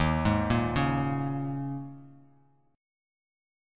D#7sus4 Chord